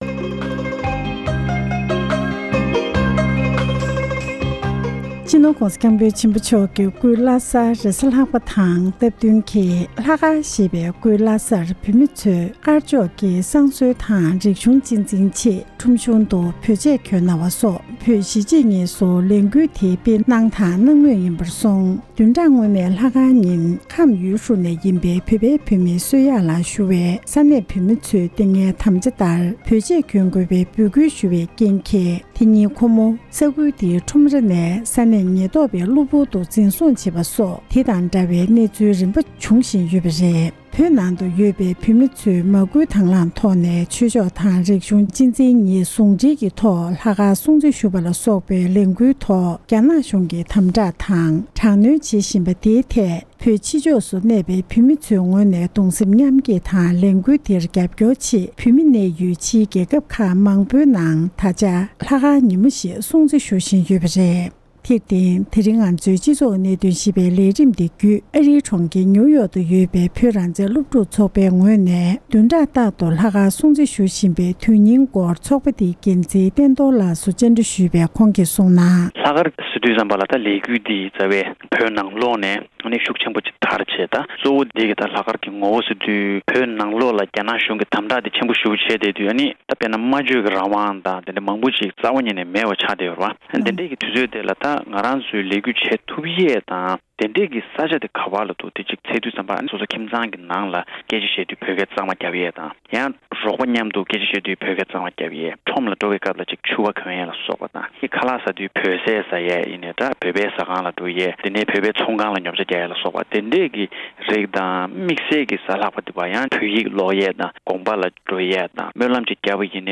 བོད་ཀྱི་འབྲེལ་ཡོད་མི་སྣ་ལ་གནས་འདྲི་ཞུས་པར་གསན་རོགས་ཞུ༎